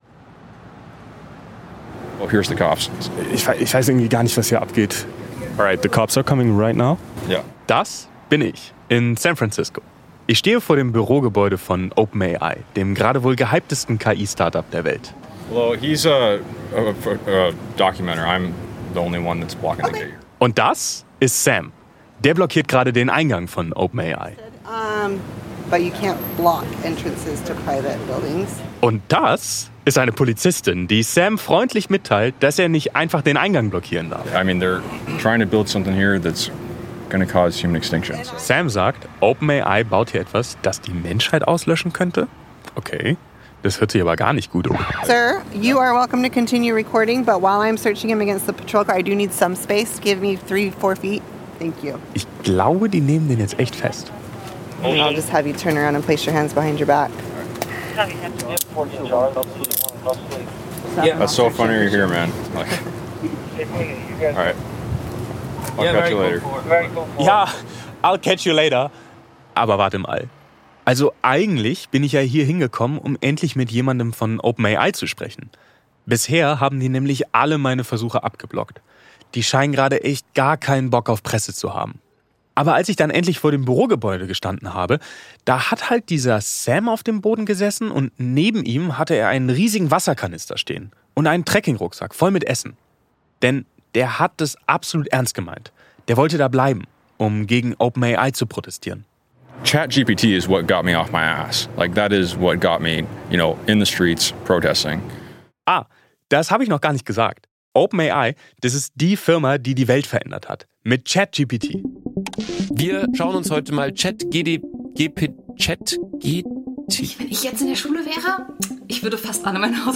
Wer steckt eigentlich hinter OpenAI – und warum wollen die unbedingt eine Art Super-KI bauen? Für diese Episode wurde KI verwendet: ChatGPT für Gespräche und einen Songtext, Suno AI für einen KI-generierten Song.